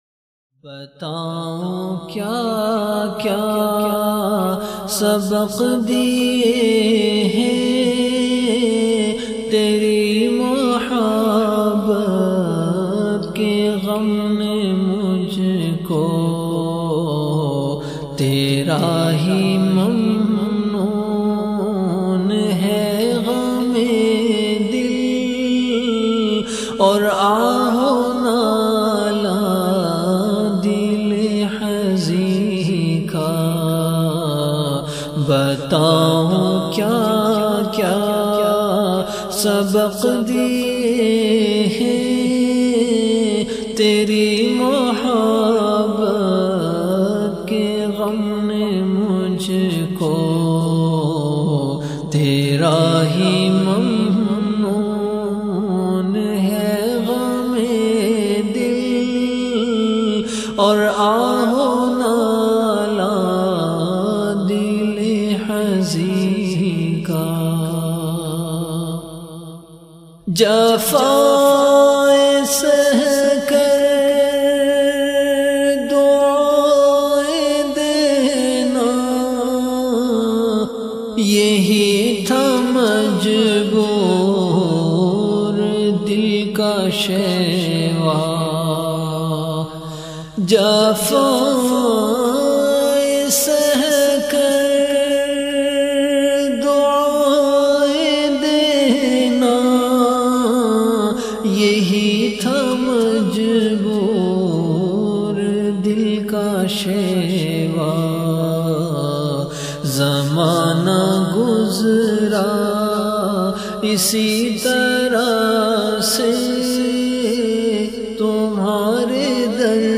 Ba Yaad e Hazrat Phoolpuri R.A (Studio Recording)
CategoryAshaar
VenueKhanqah Imdadia Ashrafia
Event / TimeAfter Isha Prayer
02.Bataoon kya kya(Studio Recording).mp3